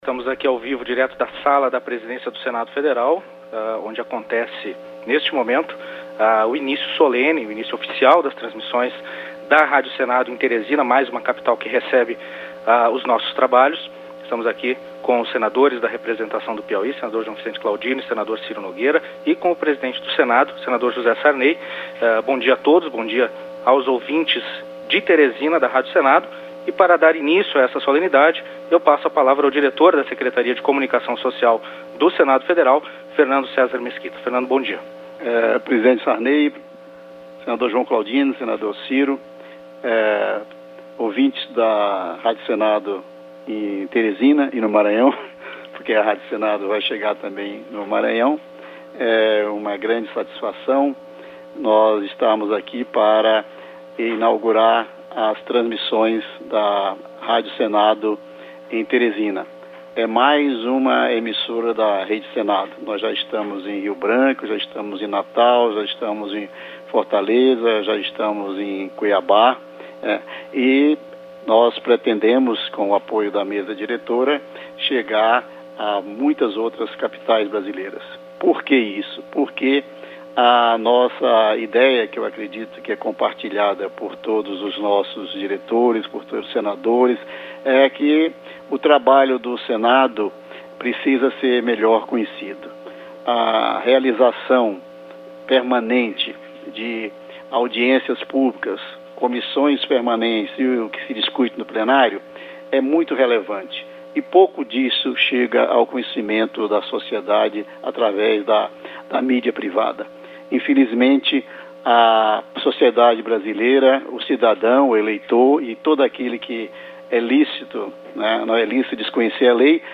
Pronunciamento